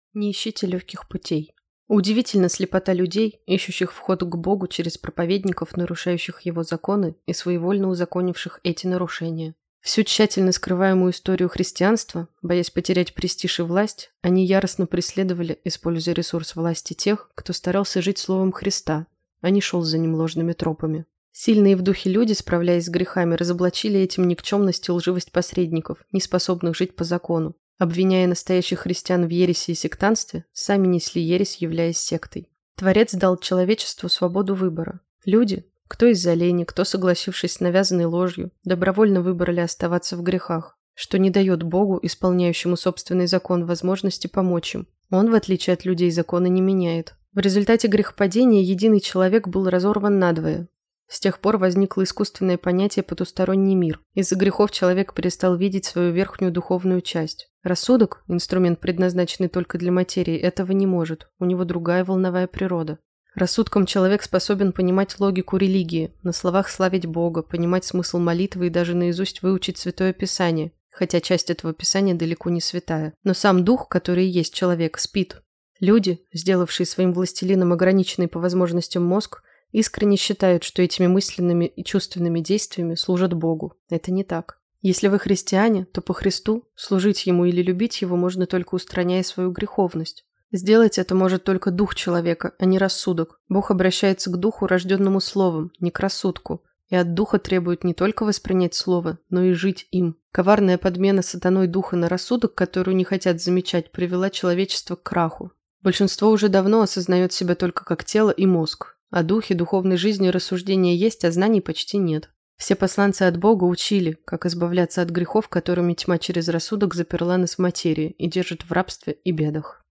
Не ищите легких путей. Аудиоверсия статьи